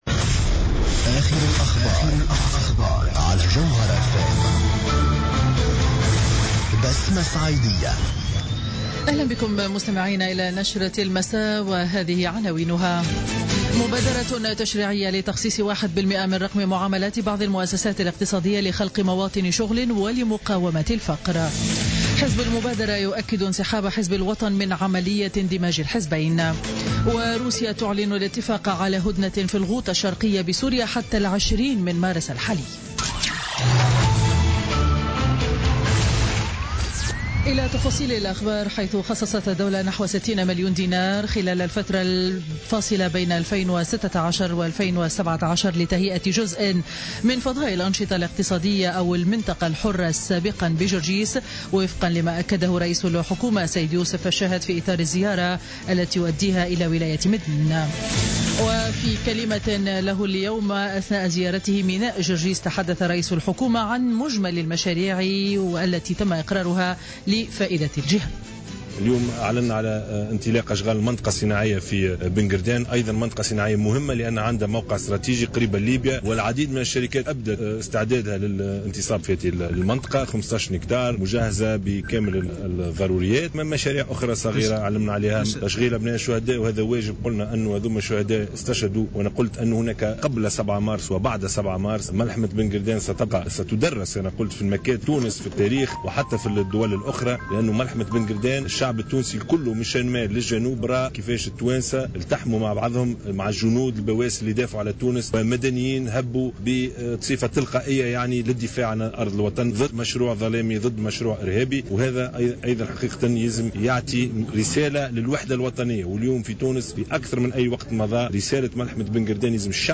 نشرة أخبار السابعة مساء ليوم الثلاثاء 7 مارس 2017